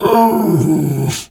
bear_pain_hurt_03.wav